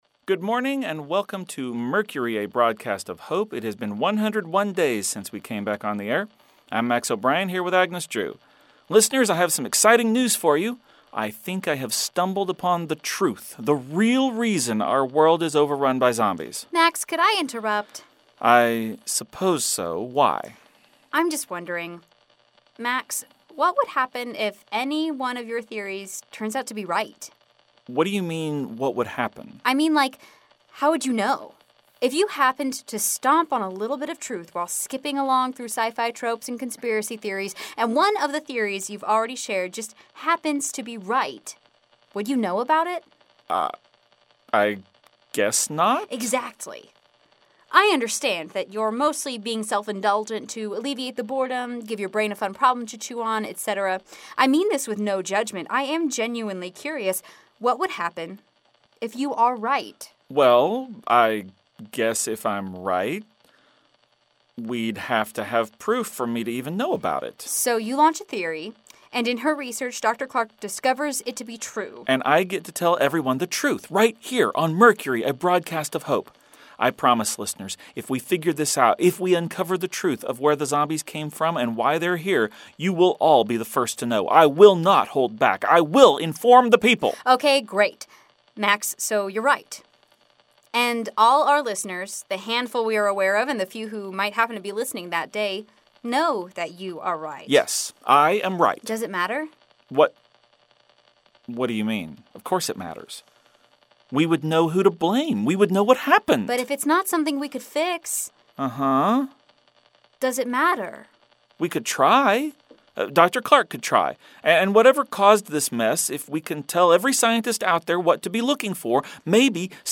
A daily audio theatre experience that tells the story of a small group of people living in a college radio station broadcasting during the zombie apocalypse.